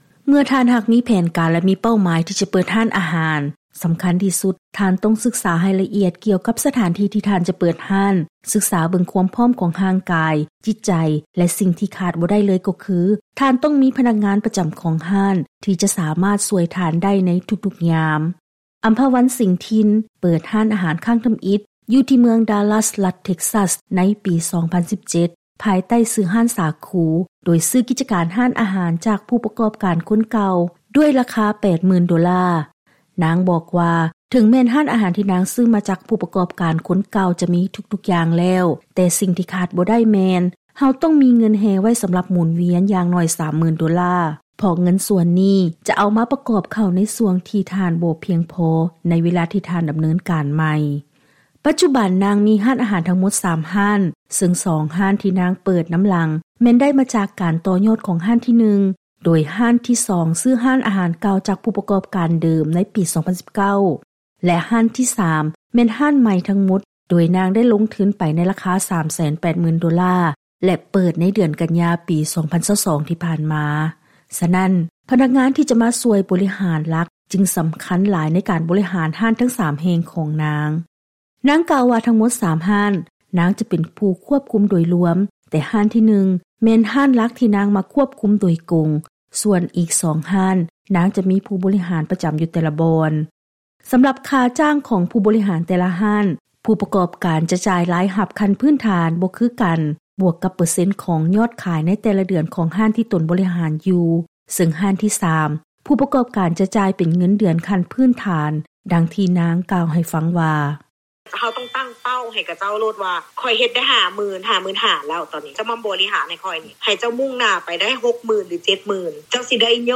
ເຊີນຟັງລາຍງານກ່ຽວກັບ ການການບໍລິຫານຮ້ານອາຫານ ທີ່ມີຫຼາຍສາຂາ.